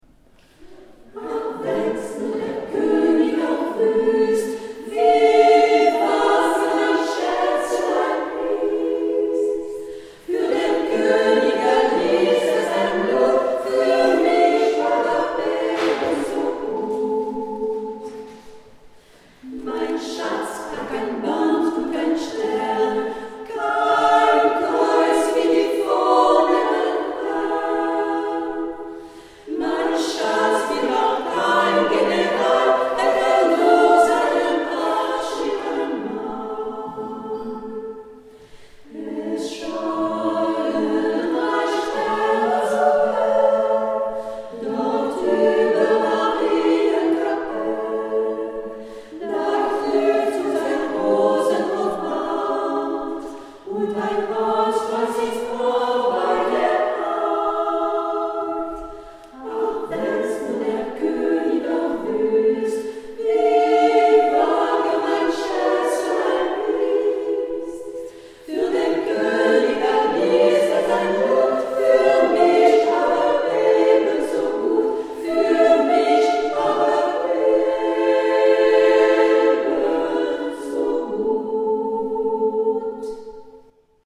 Fidèle à Lorry-Mardigny, l’Ensemble vocal féminin Fame s’est produit a cappella  dans l’église St Laurent de Mardigny dont il a apprécié l’acoustique.
Extraits du concert :